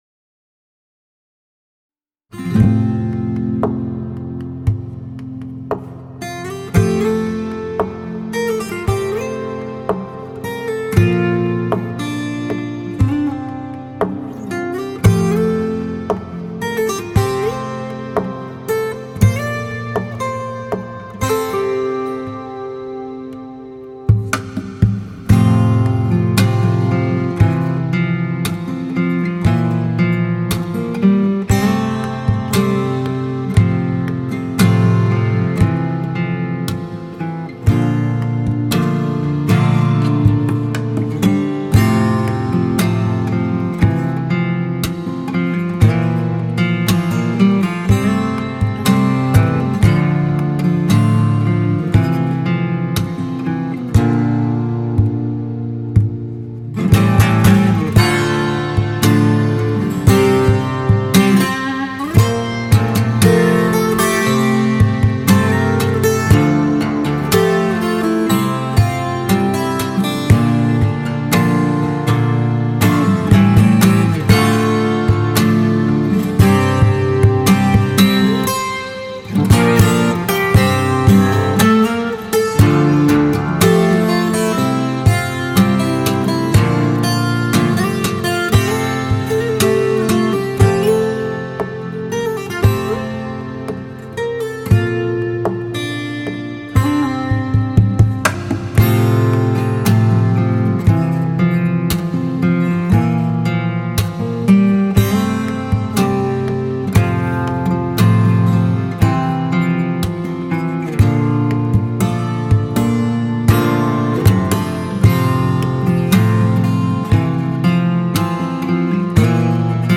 ورژن بیکلام پیانو: